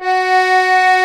F#4 ACCORD-L.wav